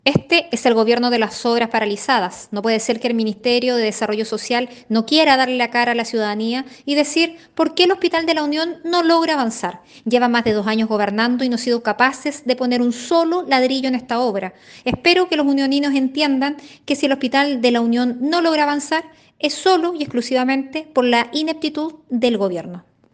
Una postura similar planteó la senadora de Renovación Nacional e Integrante de la comisión de Obras Públicas, María José Gatica, quien llamó al Gobierno a dar la cara a los habitantes de La Unión.